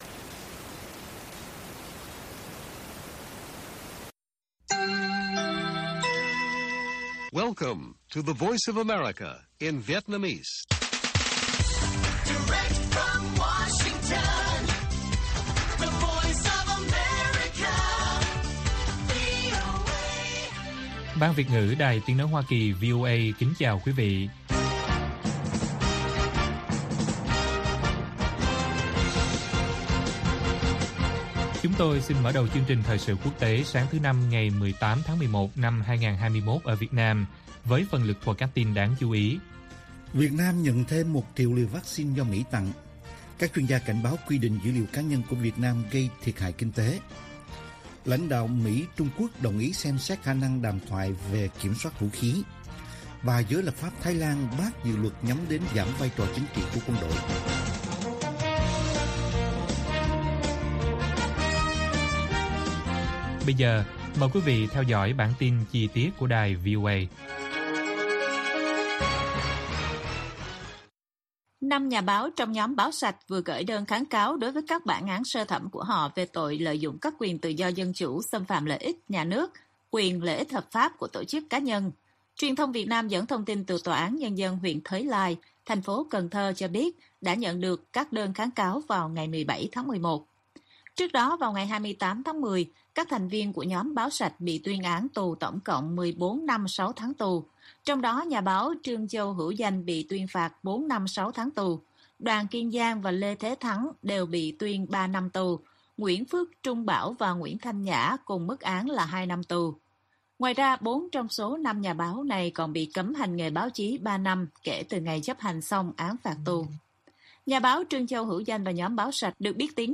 Bản tin VOA ngày 18/11/2021